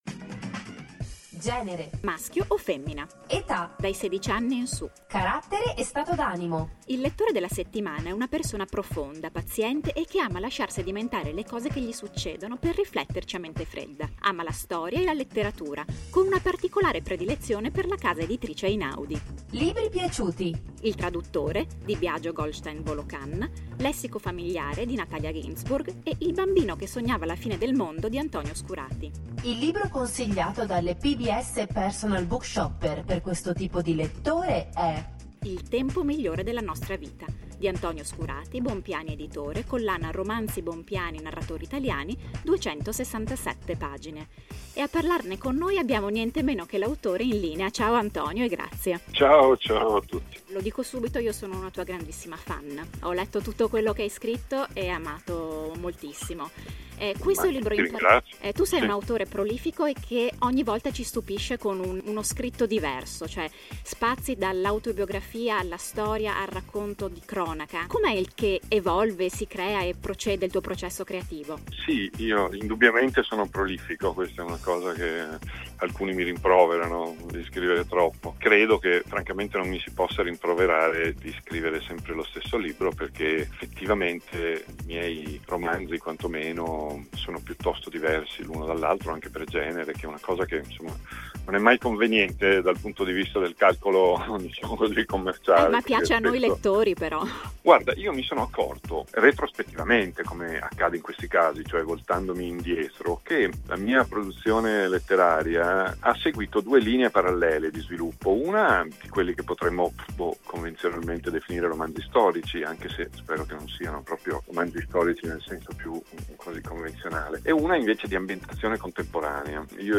Il tempo migliore della nostra vita, intervista ad Antonio Scurati
Ascolta la sua risposta premendo play,  o scarica la prima parte dell’intervista ad Antonio Scurati qui.